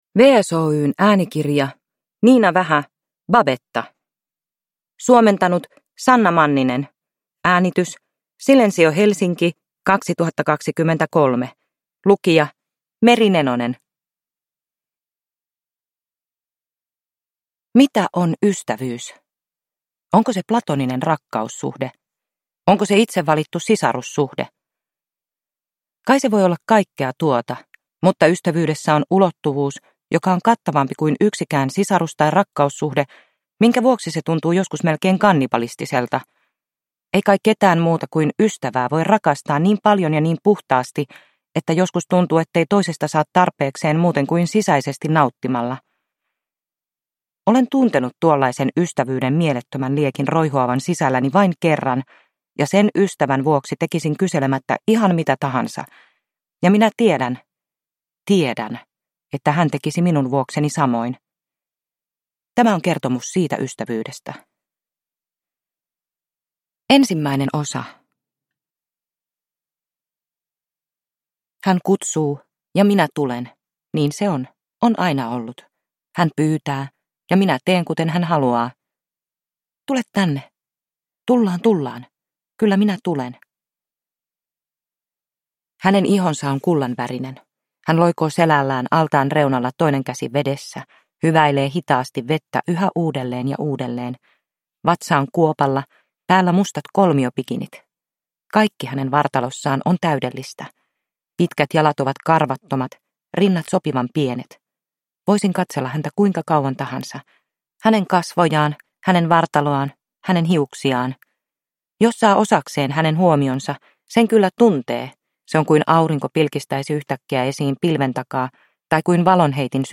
Babetta – Ljudbok – Laddas ner